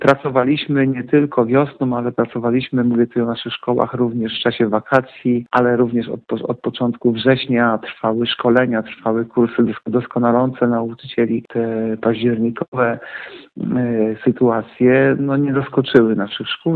– Teraz, w odróżnieniu do wiosennego zamknięcia szkół, jesteśmy przygotowani – mówi Artur Urbański, zastępca prezydenta Ełku.